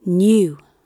new-gb.mp3